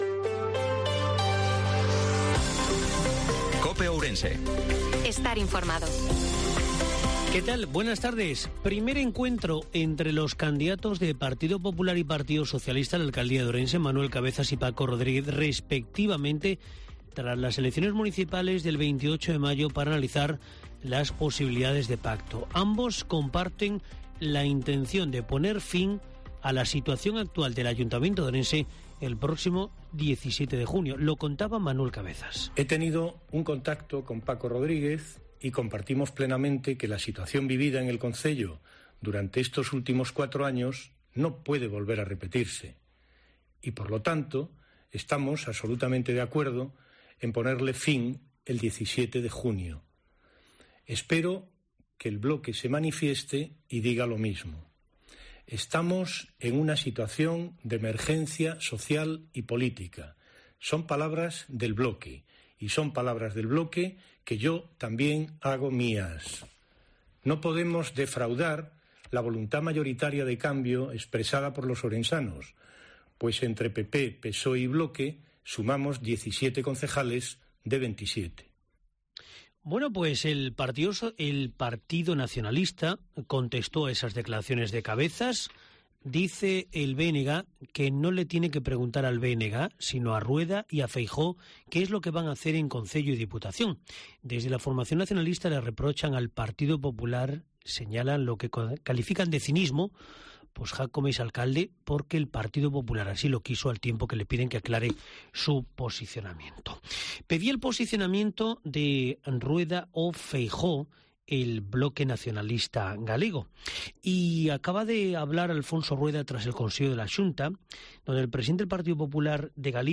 INFORMATIVO MEDIODIA COPE OURENSE-01/06/2023